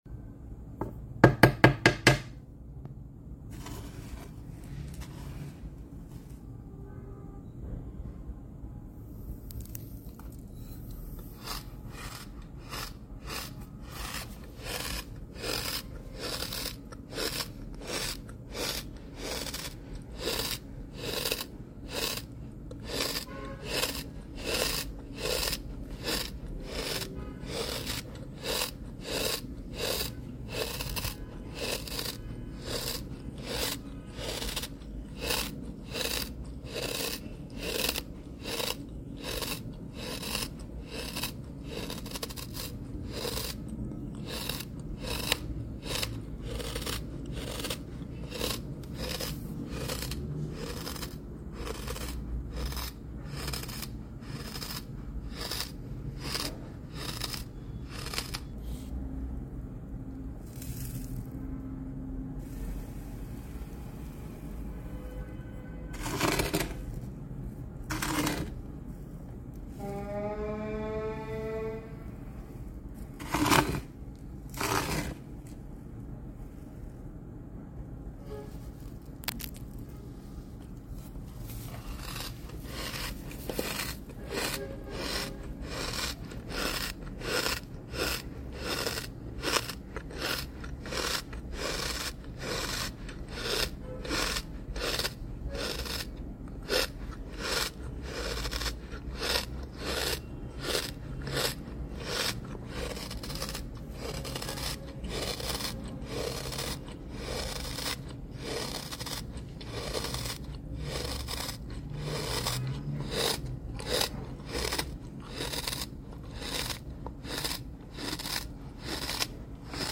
Sand Sound Wonders. Sand Therapy. sound effects free download